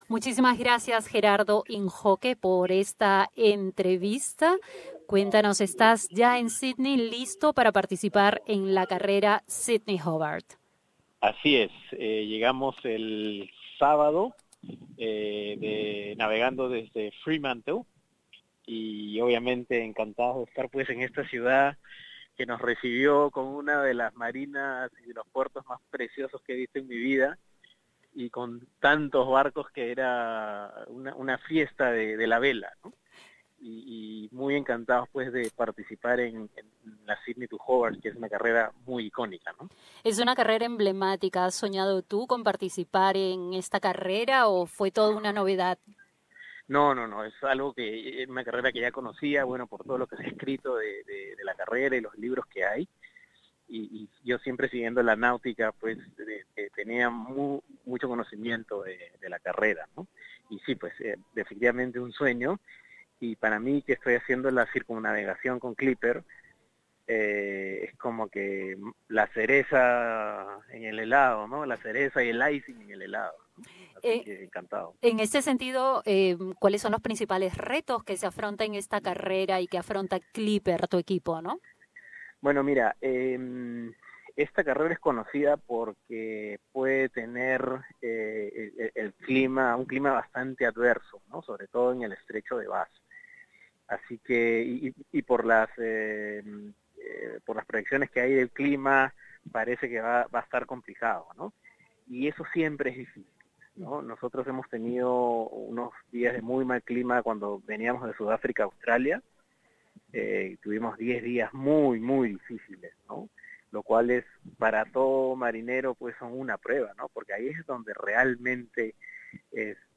Escucha la entrevista con Radio SBS.